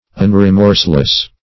Search Result for " unremorseless" : The Collaborative International Dictionary of English v.0.48: Unremorseless \Un`re*morse"less\, a. [Pref. un- not (intensive) + remorseless.]